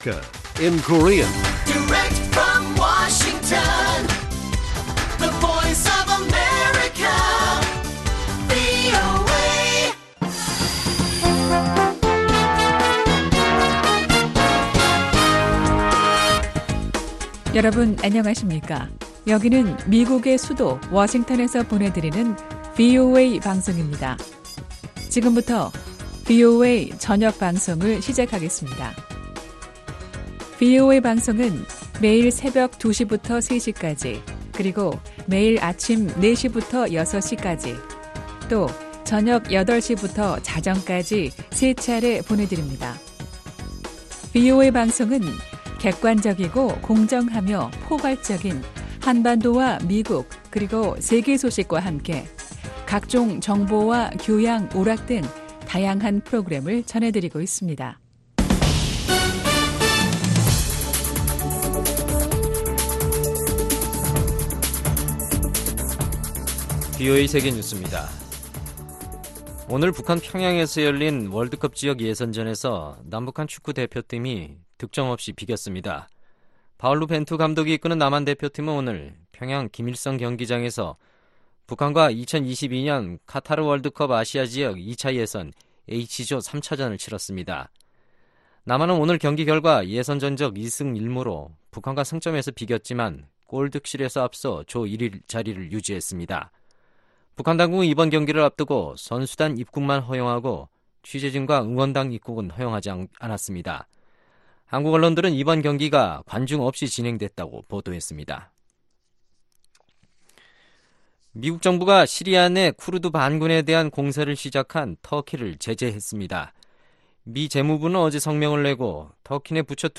VOA 한국어 간판 뉴스 프로그램 '뉴스 투데이', 2019년 10월 15일 1부 방송입니다. 유엔총회에서 여러 나라들이 북한의 탄도미사일 발사를 규탄하고 있습니다. 북한은 한반도 안보를 위협하는 것은 미국이라고 반박했습니다. 타이완이 유엔의 대북 제재와 관련해 지난해 약 300만 달러의 자금을 동결했다고 자금세탁방지기구가 밝혔습니다.